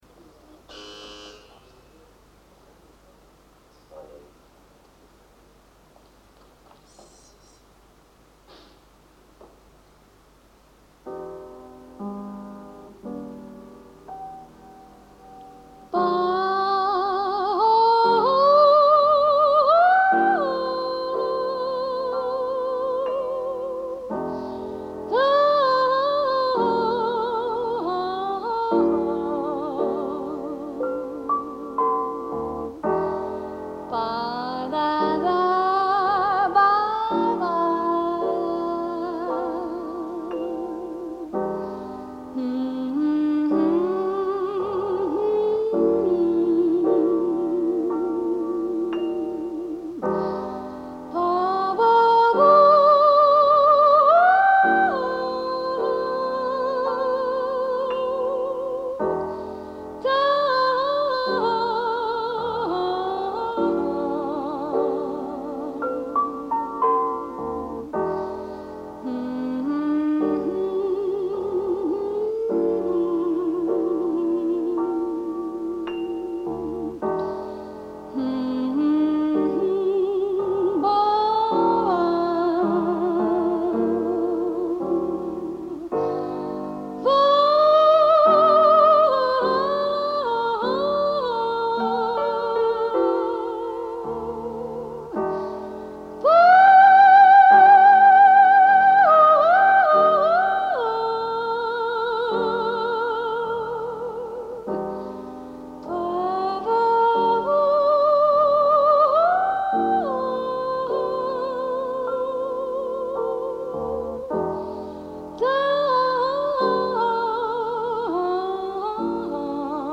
piano
In-between the full takes, there are some incomplete ones.